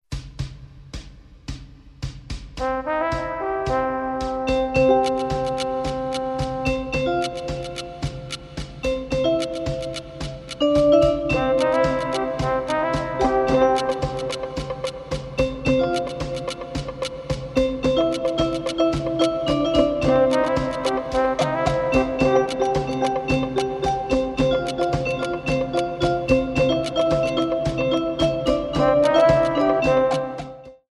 Piano Music for Modern Dance Class